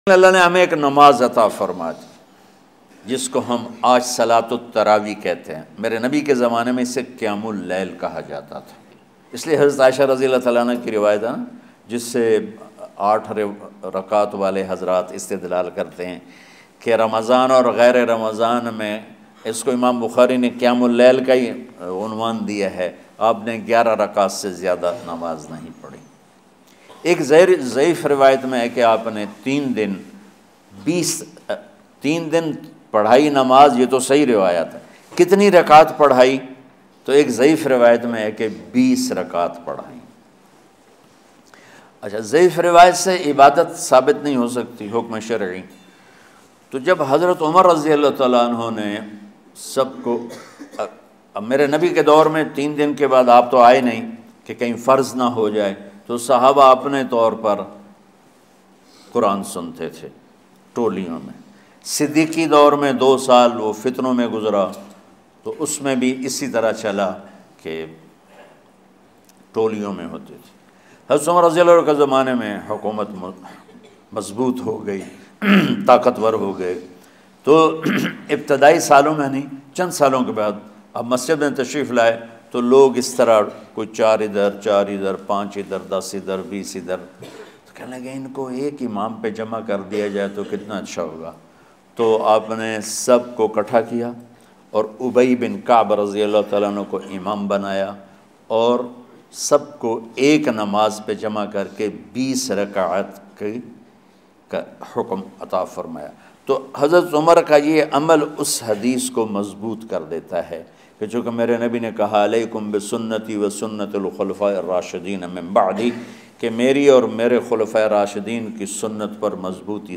Namaz-e-Taraweeh Kitni Parhni Chahiye, listen latest bayan by Maulana Tariq Jameel about Ramadan.